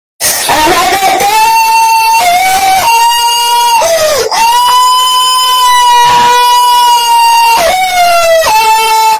Ambatukam Loud Sound Effect Free Download
Ambatukam Loud